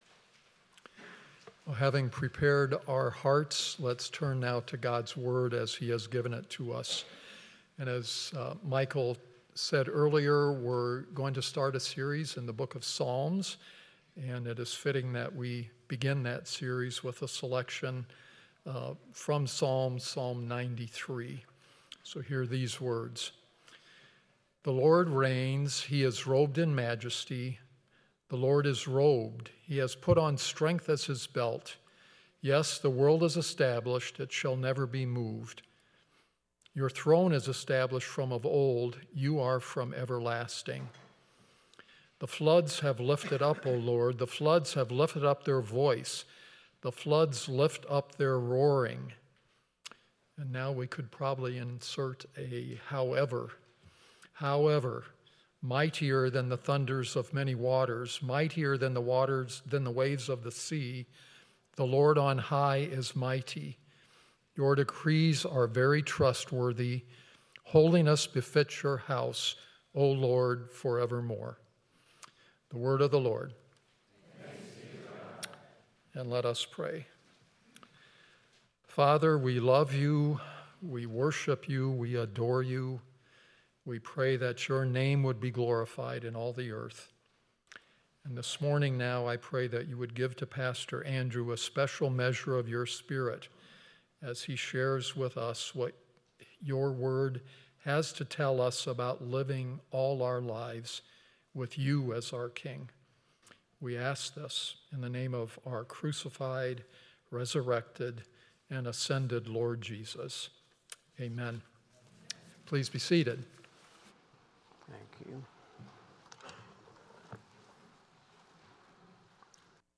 6.1.25 sermon.m4a